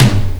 prcTTE44013tom.wav